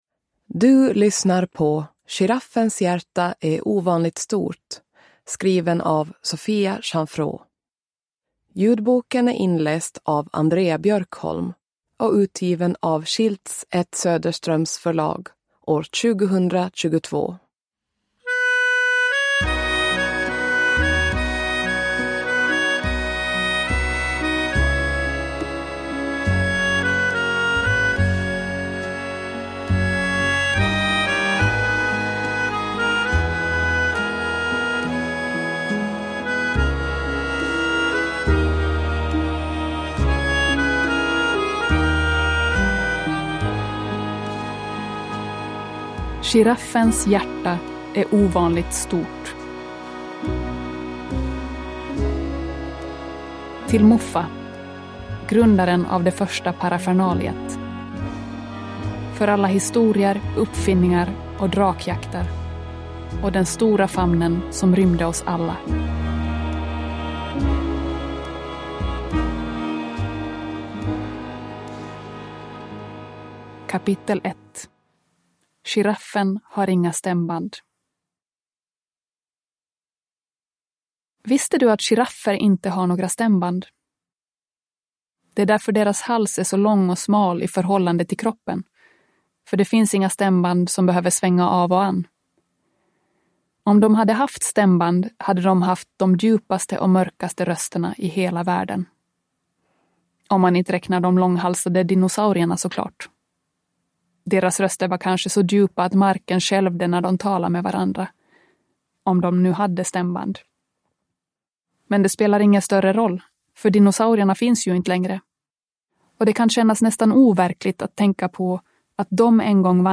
Giraffens hjärta är ovanligt stort – Ljudbok – Laddas ner